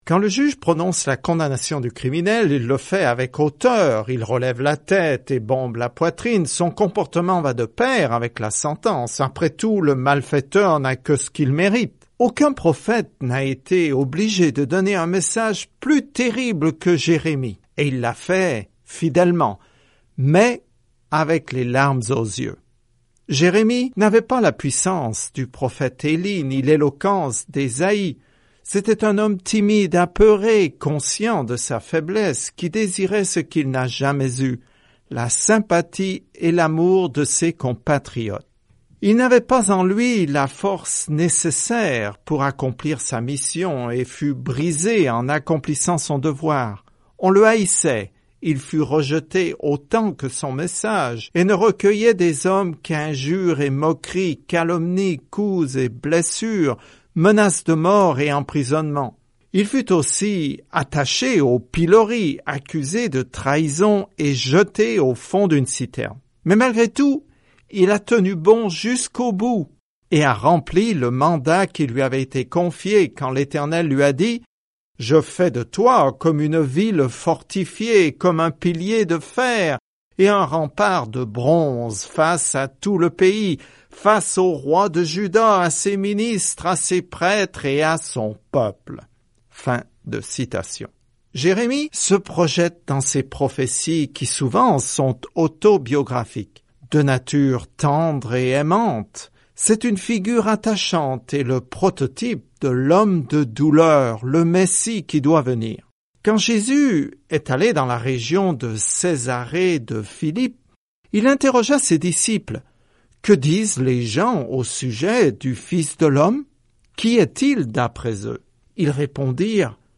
Écritures Jérémie 1:1-3 Commencer ce plan Jour 2 À propos de ce plan Dieu a choisi Jérémie, un homme au cœur tendre, pour délivrer un message dur, mais le peuple ne reçoit pas bien le message. Parcourez quotidiennement Jérémie en écoutant l’étude audio et en lisant certains versets de la parole de Dieu.